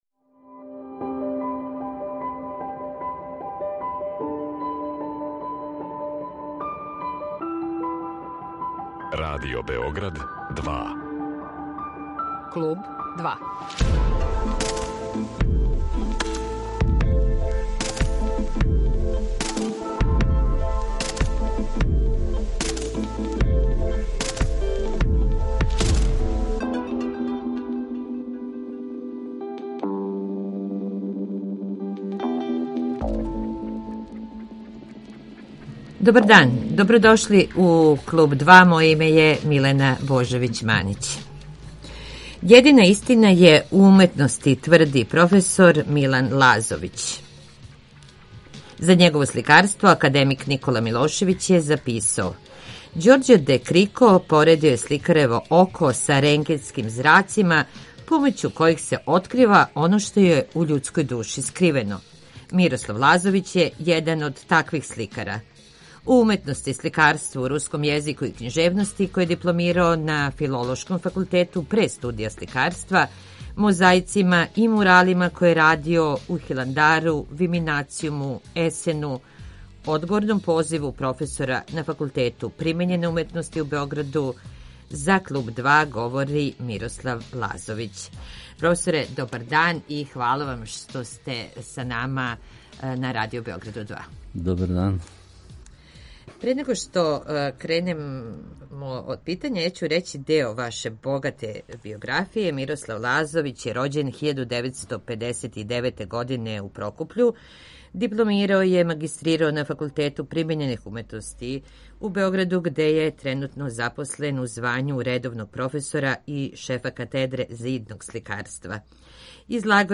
Гост емисије је сликар